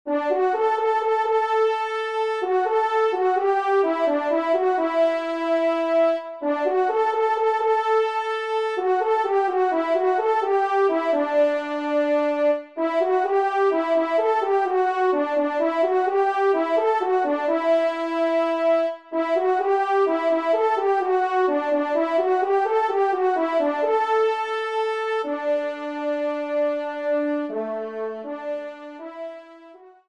Solo Ton Simple